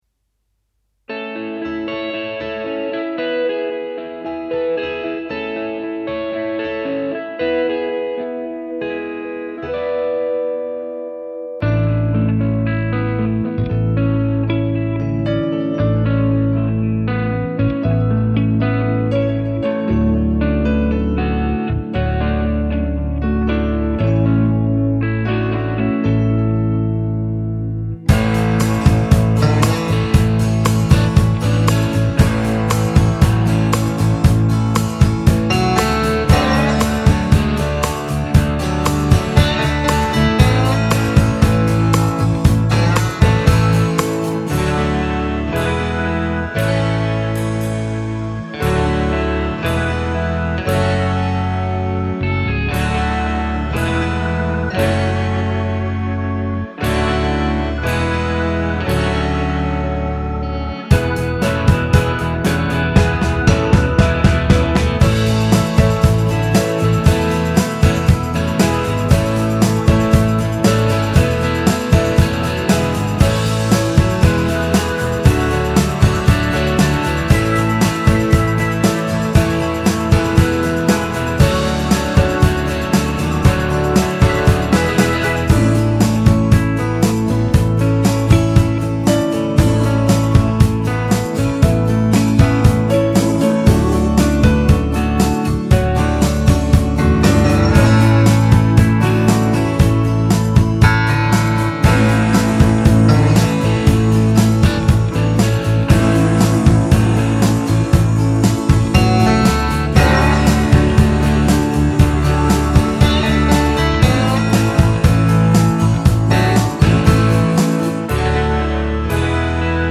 aerien - nostalgique - calme - guitare electrique - dobro